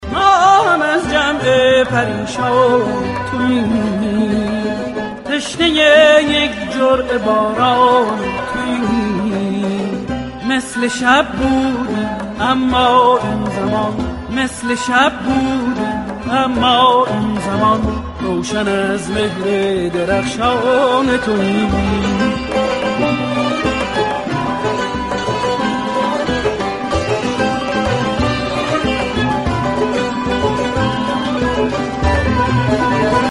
رینگتون زیبا، شاد و با کلام